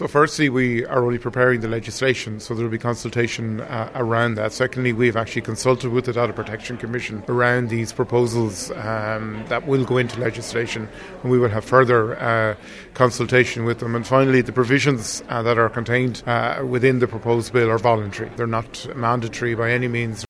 Minister Dara Calleary is defending the decision……….